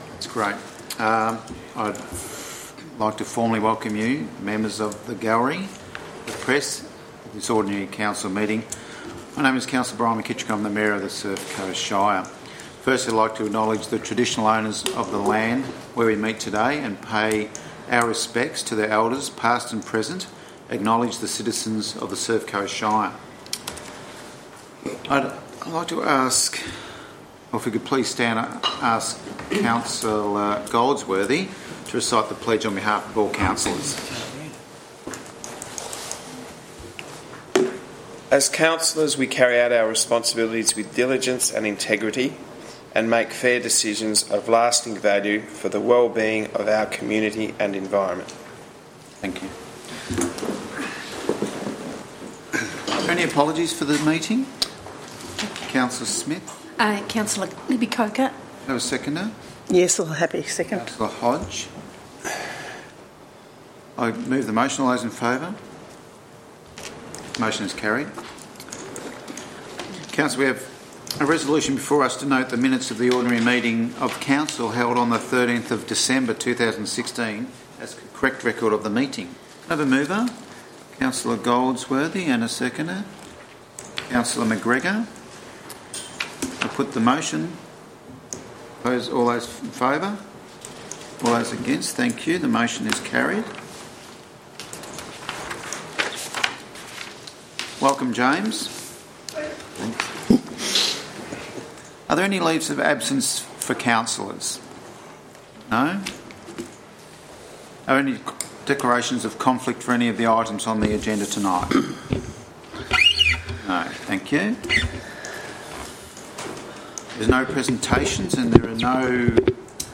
Council Meeting 24 January 2017
Audio-Recording-Ordinary-Council-Meeting-24-January-2017.mp3